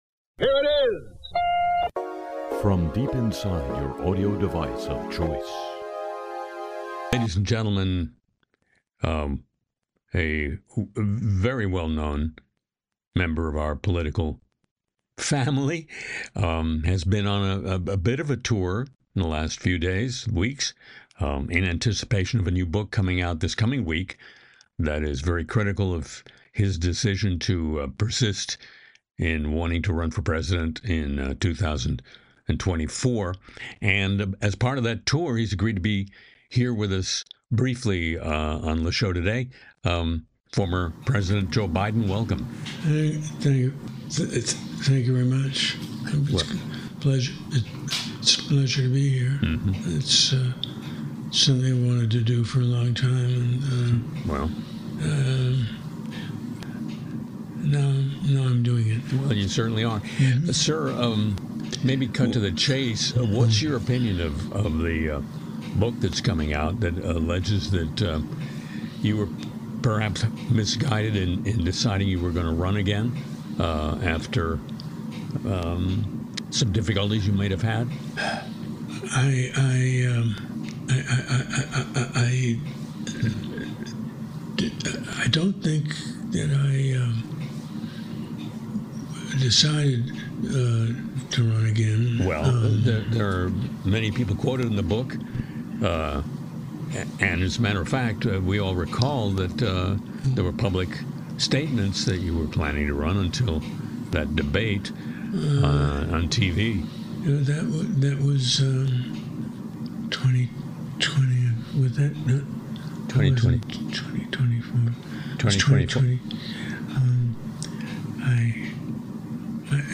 Part-time New Orleans resident Harry Shearer hosts a look at the worlds of media, politics, cyberspace, sports and show business while providing an eclectic array of music along the way.